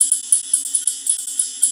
Ride 02.wav